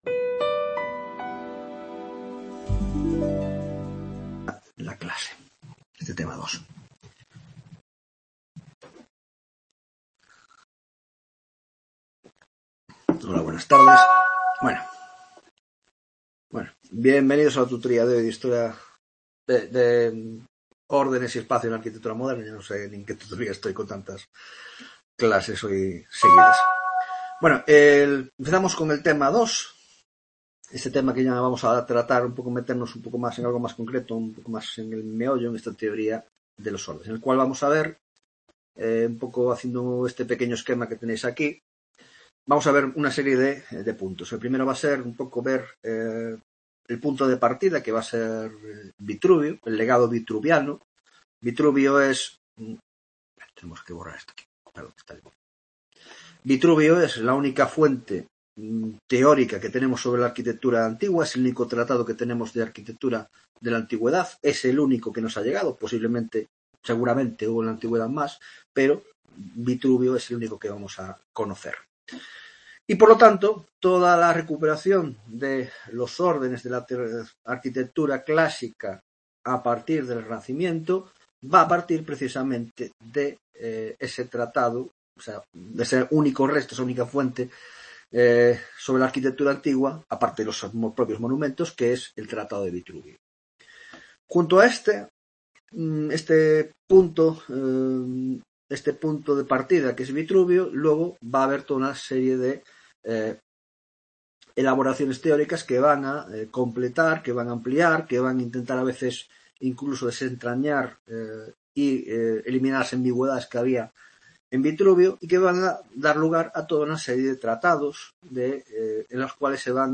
3ª Tutoria de Órdenes y Espacio en la Arquitectura Moderna (grado de Historia del Arte): La Teoria de los Ordenes (1ª parte): 1) El Legado de Vitrubio; 2) Teoria de los en Italia: Los libros de Ordenes: De Alberti a Serlio